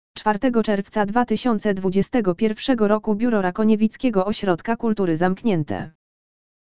Załączniki LEKTOR AUDIO 04.06.2021 BIURO RAKONIEWICKIEGO OŚRODKA KULTURY ZAMKNIĘTE!